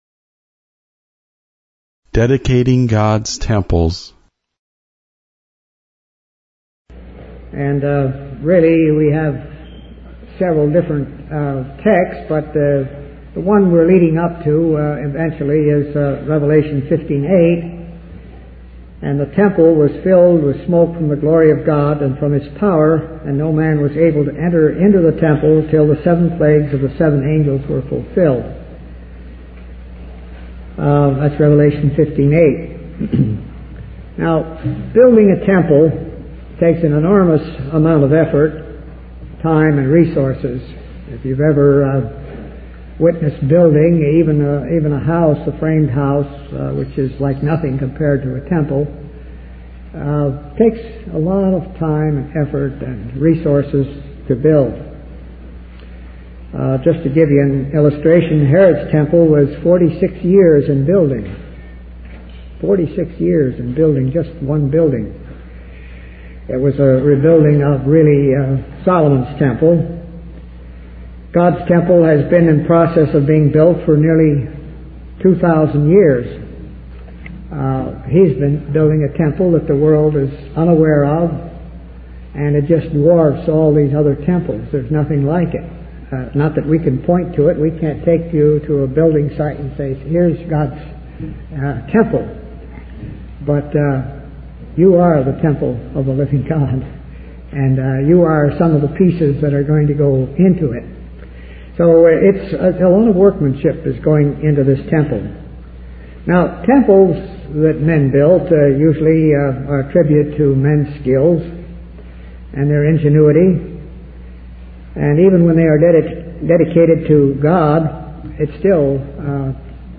From Type: "Discourse"
Given at Indiana/Ohio Convention 1997